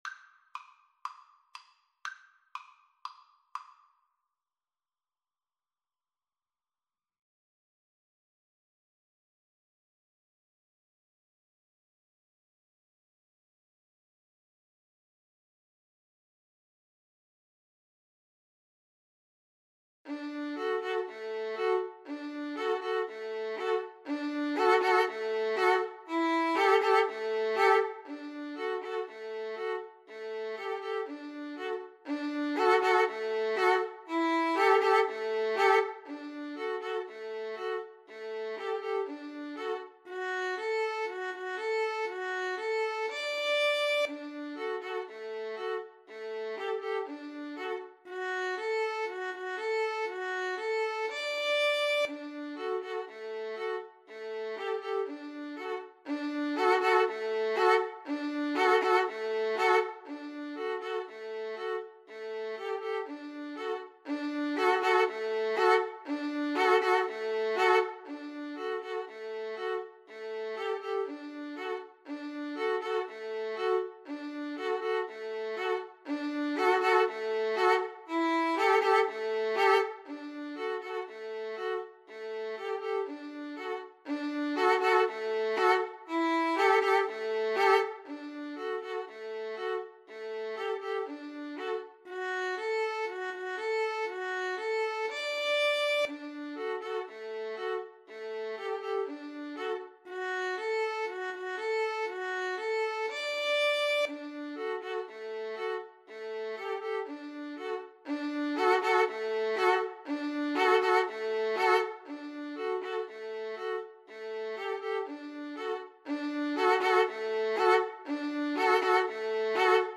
Fast =c.120